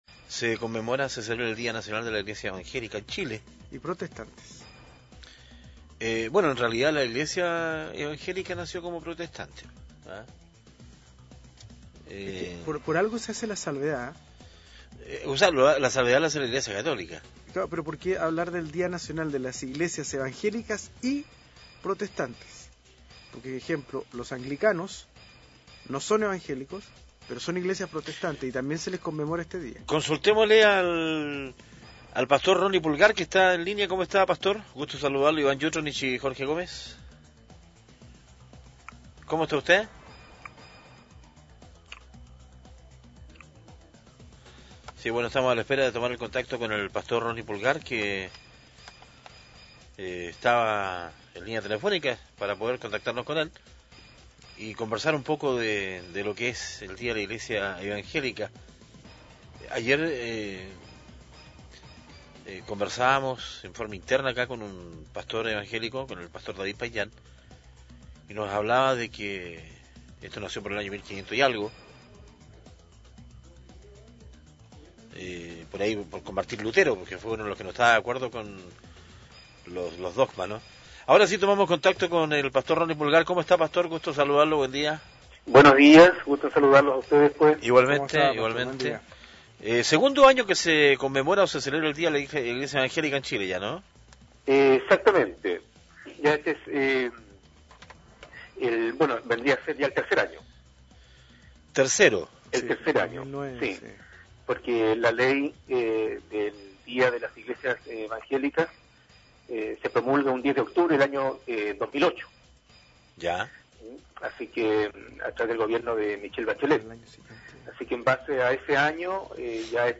Entrevistas de Pingüino Radio - Diario El Pingüino - Punta Arenas, Chile